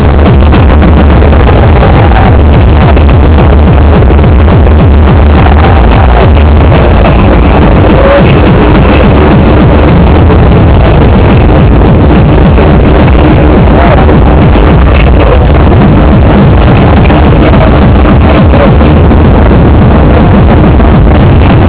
technival vingtneufaout deuxmillecinq crucey
Le teknival c’est un grand rassemblement de musique électronique, dégustation de plats épicés, boissons enivrantes et plus si affinités. Cette musique envahi le corps à en faire perdre le rythme cardiaque, une danse individuelle à faire oublier les 40000 personnes autour.